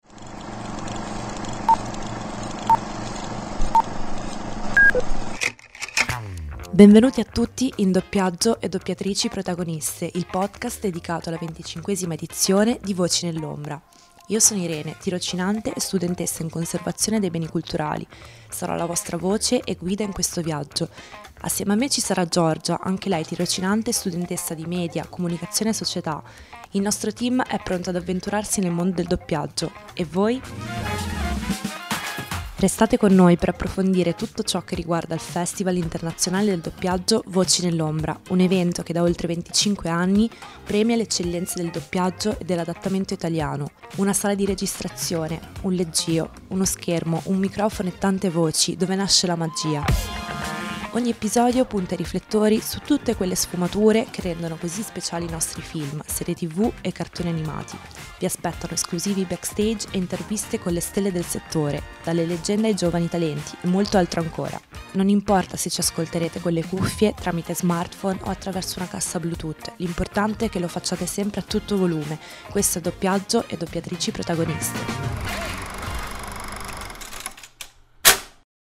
Music: "New York Minute'" By Reaktor Productions